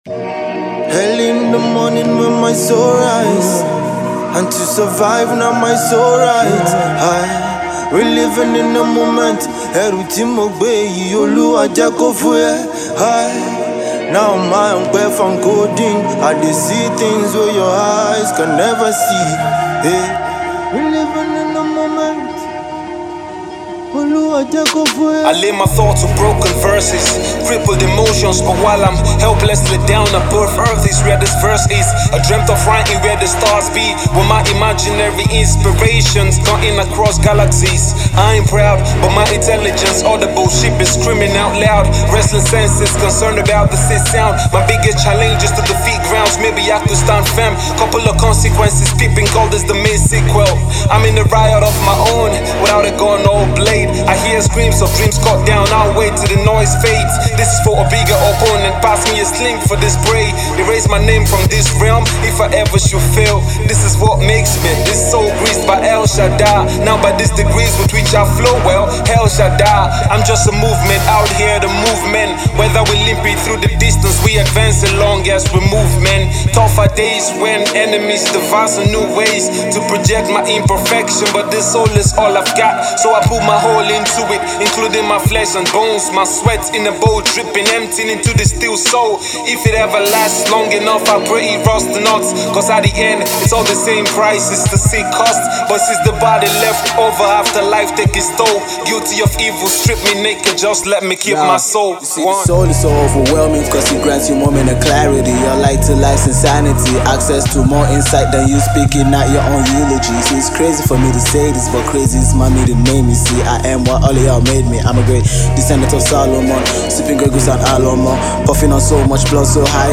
Hip-Hop
Its a Good rap song